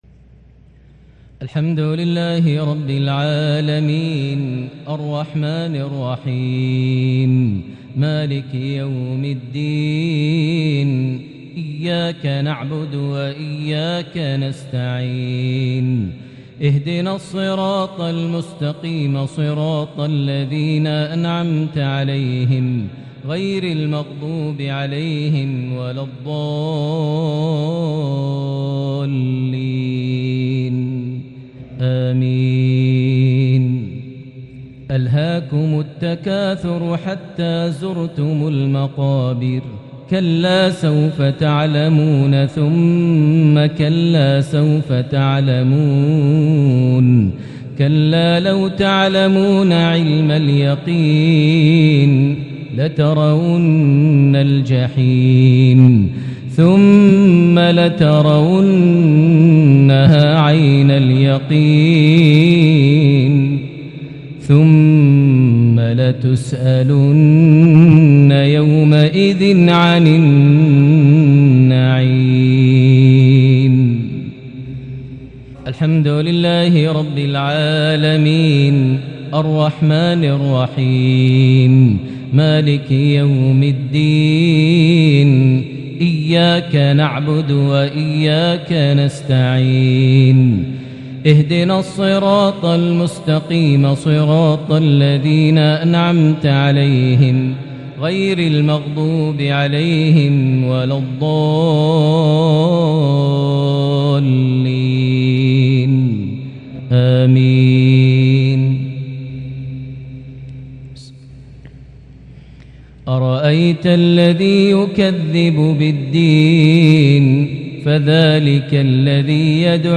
maghrib 1-6-2022 prayer from Surah At-Takathur + Surah Al-Maun > 1443 H > Prayers - Maher Almuaiqly Recitations